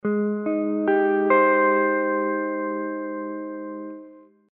ab_major7.mp3